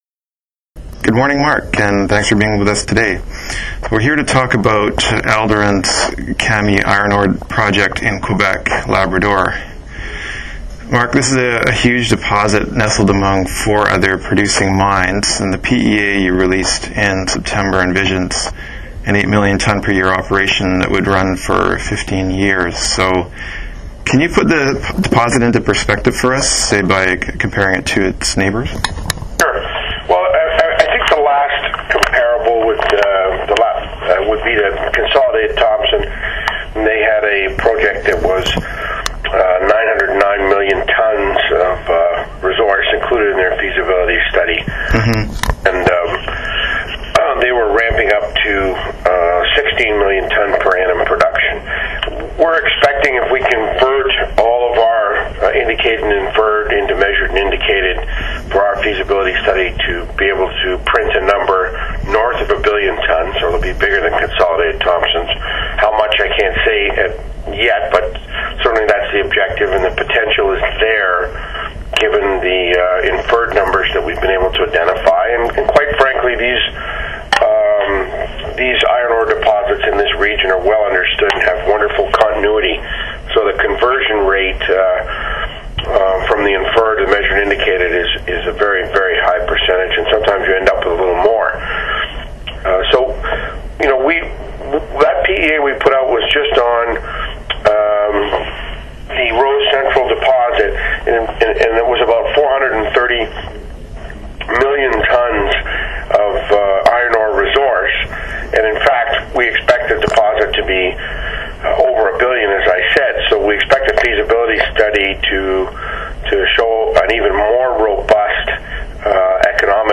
alderon interview
alderon-interview.mp3